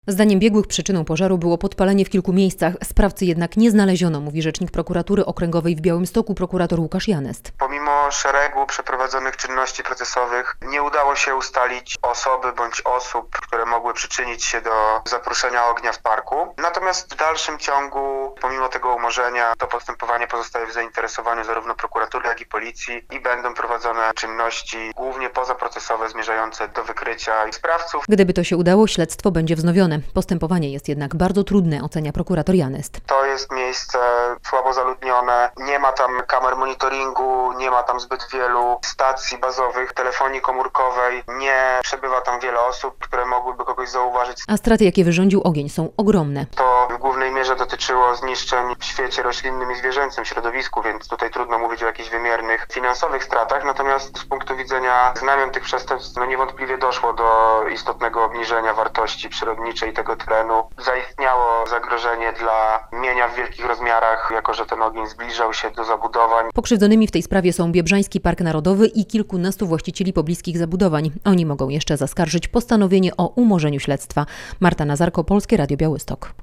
Umorzono śledztwo ws. pożaru w Biebrzańskim Parku Narodowym - relacja